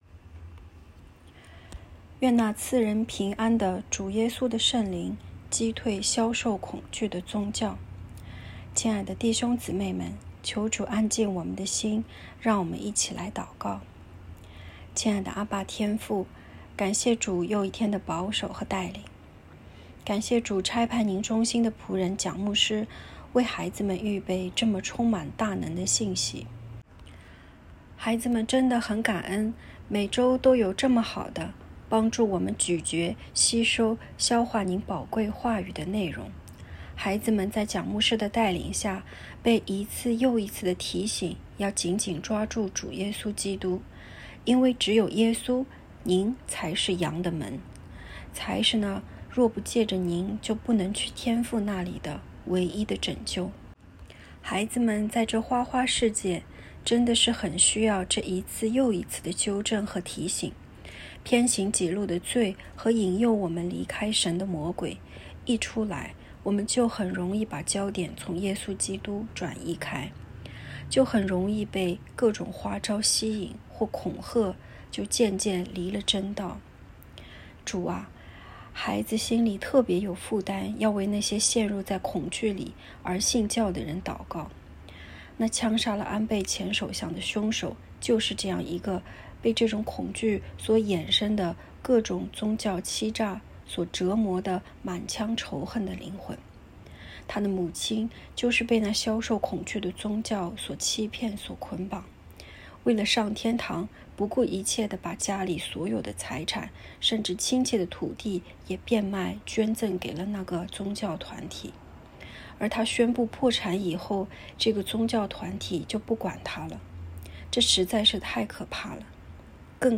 ✨晚祷时间✨7月24日（周日）